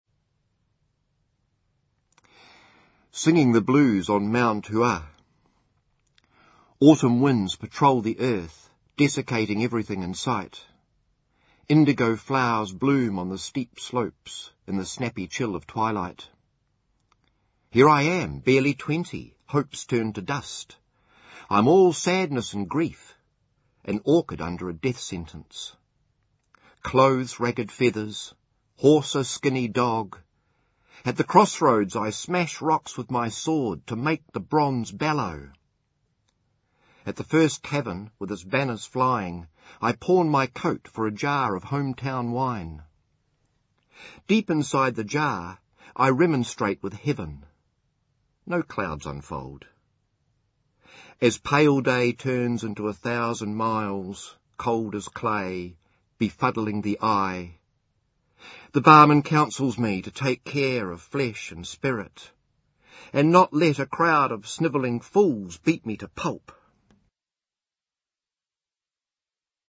reading Singing the Blues on Mt Hua